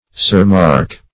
Surmark \Sur"mark`\ (s[^u]r"m[aum]rk`), n.